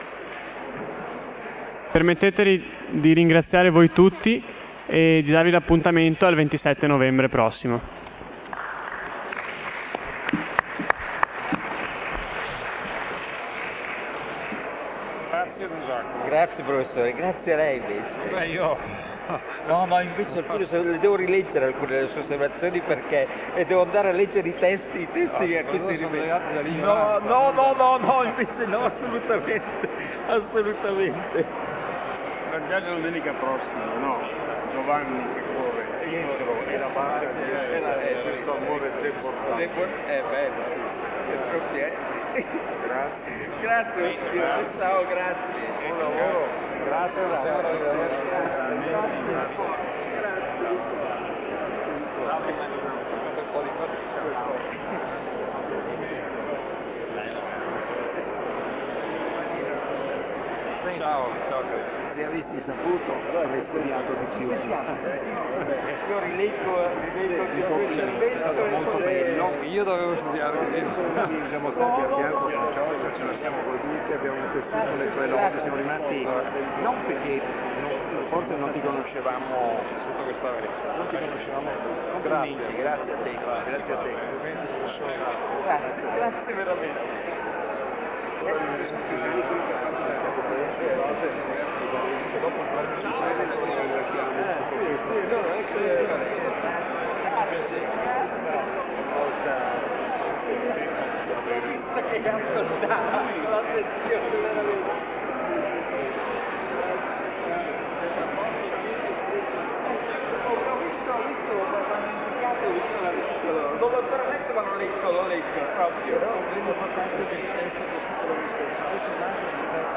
Convegni sull’attualità di Sant’Agostino
martedì 17 aprile 2007 – ore 17.45, aula magna – palazzo del Bo